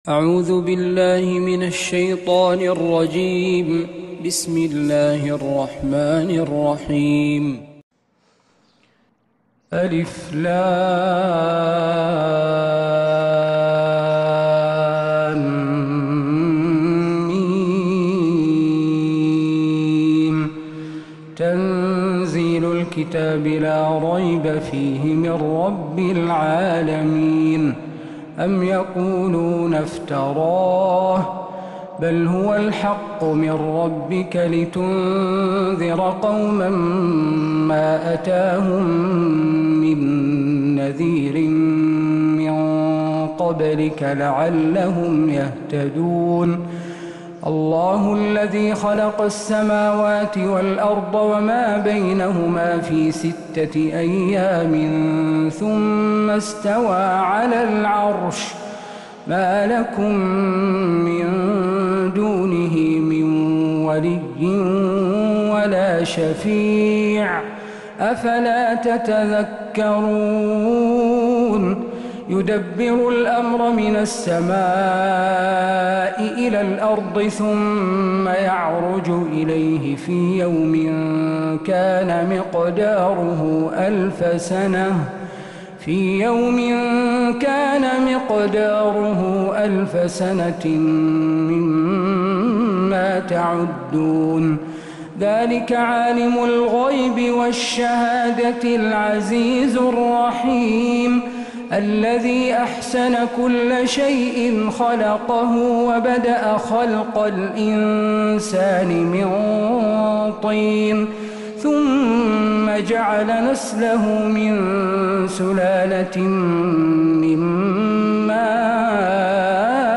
سورة السجدة من فجريات الحرم النبوي